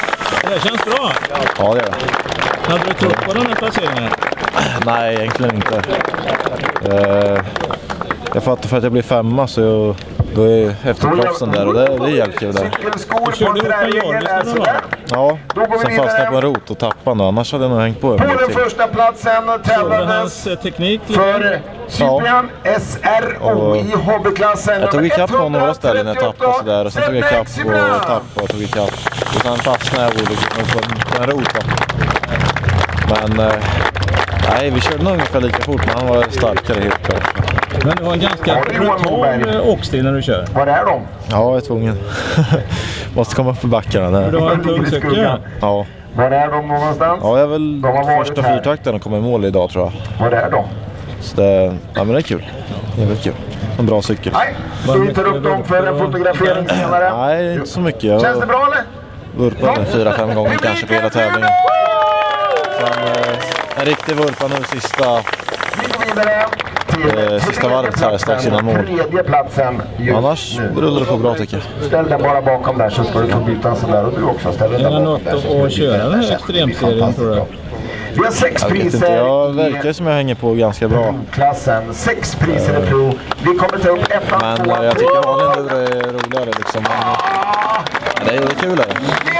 Dålig ljudkvalité.